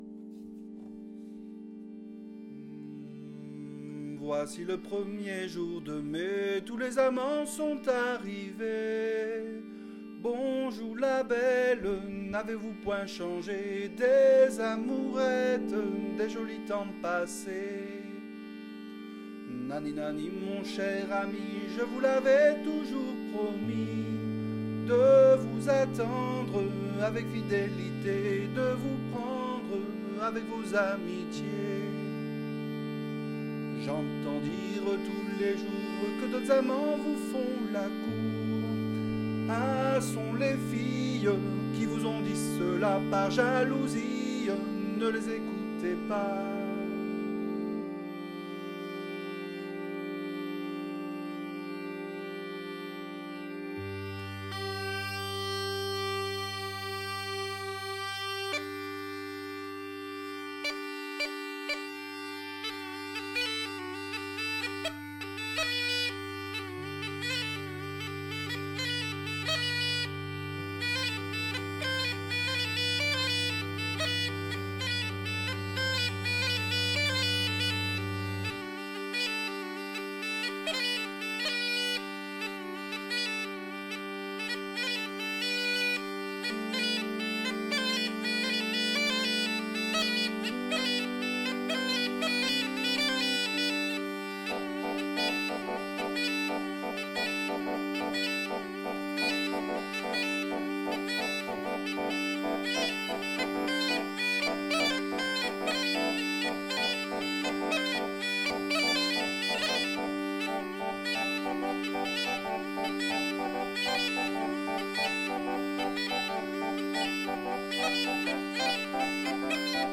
Genre : chanson-musique
Effectif : 1
Type de voix : voix d'homme
Production du son : chanté
Instrument de musique : boha
Danse : rondeau